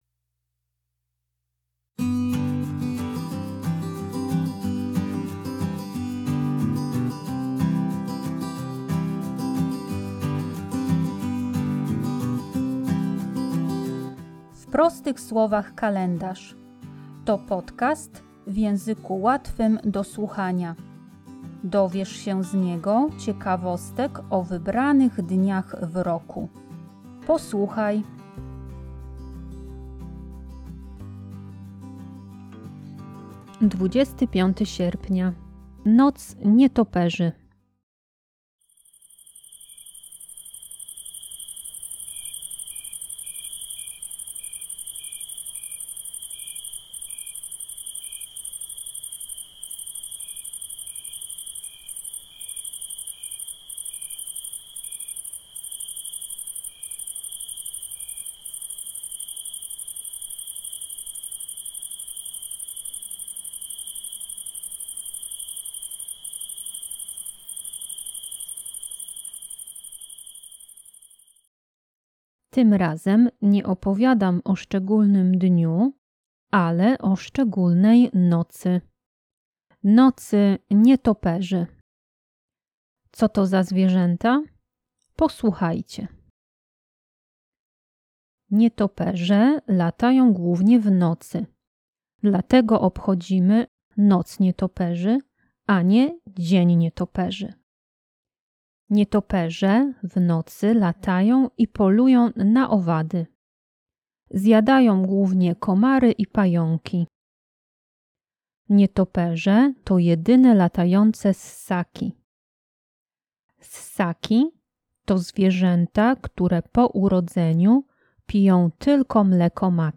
W podcaście usłyszycie dźwięki nocy.